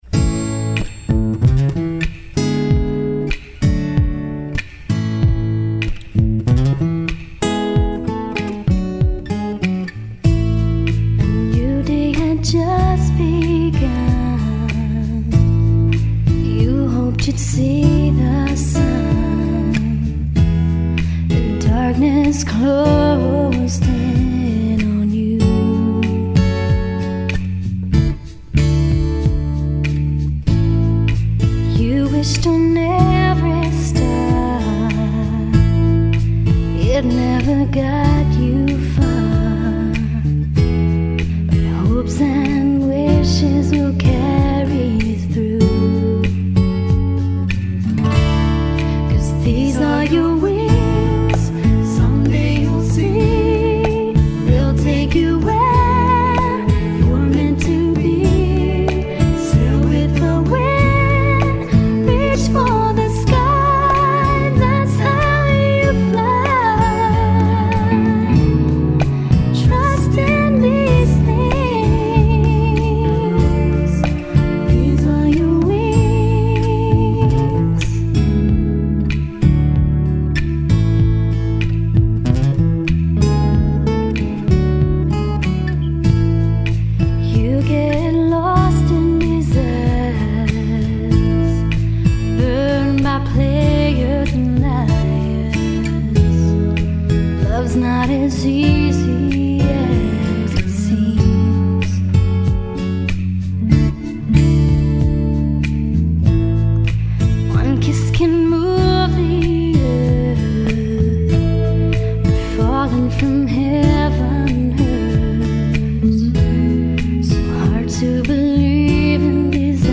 (Lead vocals by guest performers.)